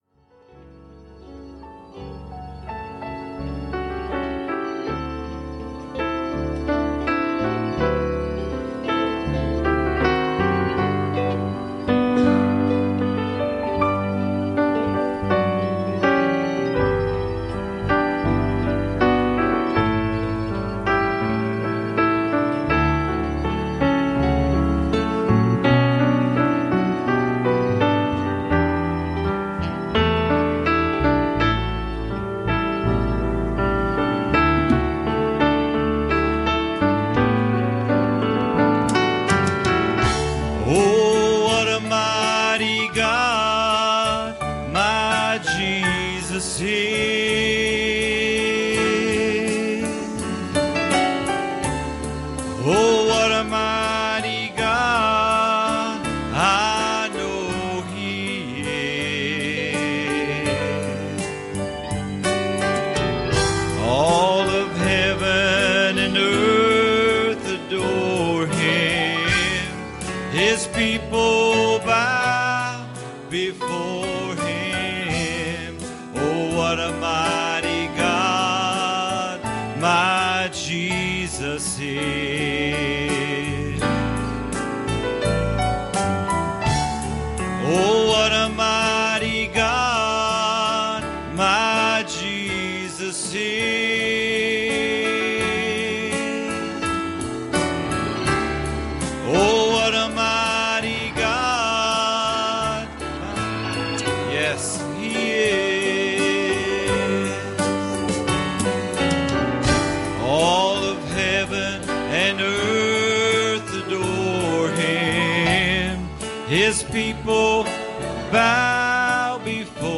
Series: Sunday Morning Services
Service Type: Sunday Morning